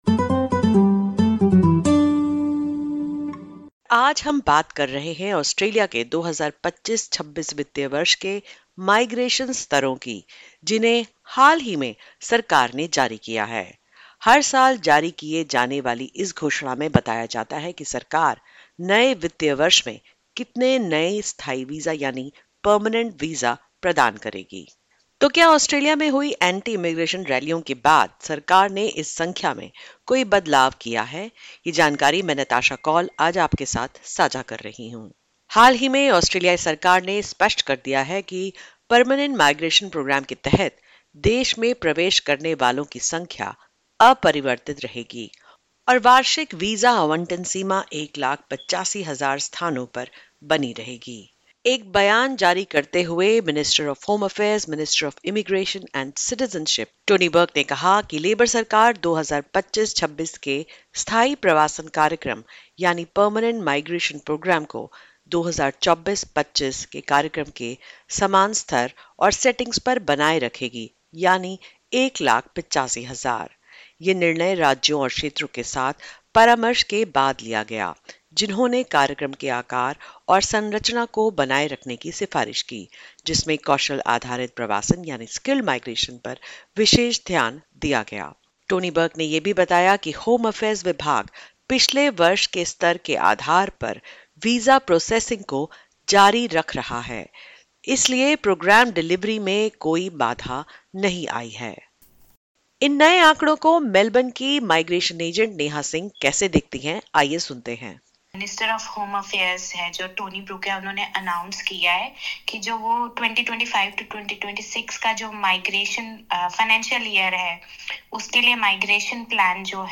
(Disclaimer: The views/opinions expressed in this interview are the personal views of the individual.